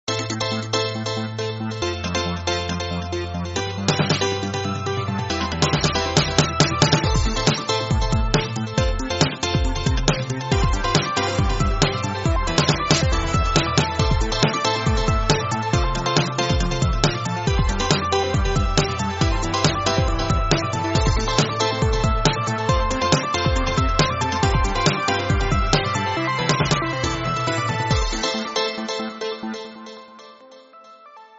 Pista musical
Estilo: Pop
Pista musical para jingles estilo “pop”